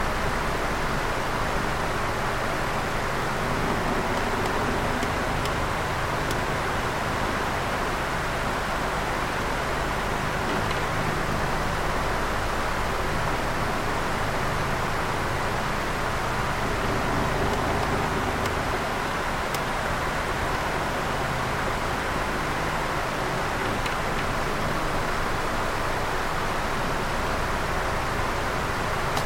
家用 " 风扇慢速
描述：台式风扇速度慢
标签： 空气 风扇 风扇 空调 空调 通风 鼓风机 空调
声道立体声